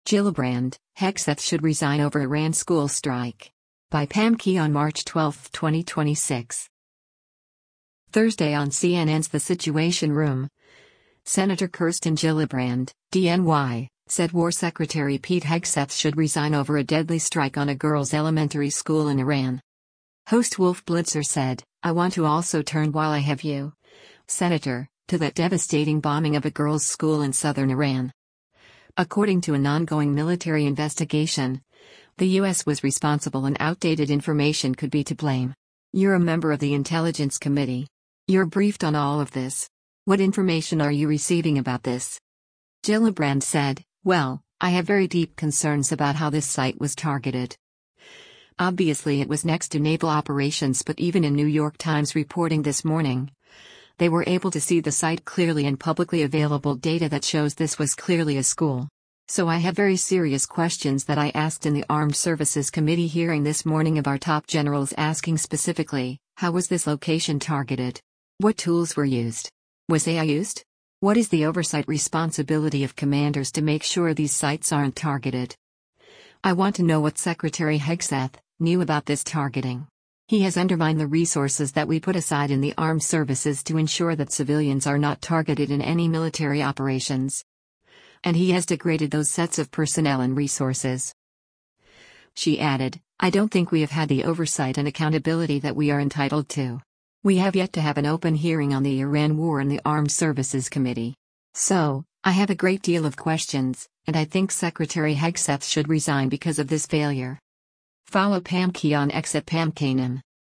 Thursday on CNN’s “The Situation Room,” Sen. Kirsten Gillibrand (D-NY) said War Secretary Pete Hegseth should resign over a deadly strike on a girls’ elementary school in Iran.